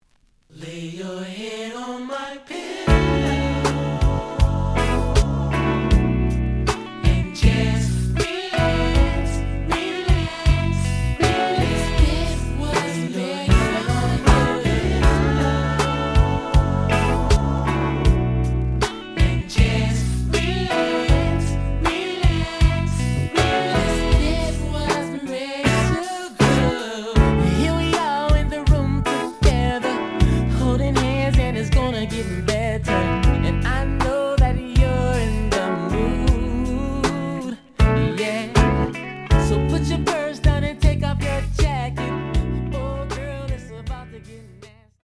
The audio sample is recorded from the actual item.
●Genre: Hip Hop / R&B
Slight edge warp.